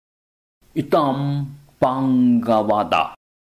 唱誦